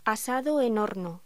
Locución: Asado en horno